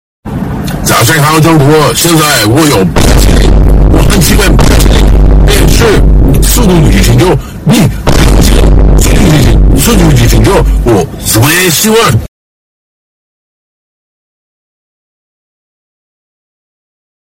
Loud Bing Chilling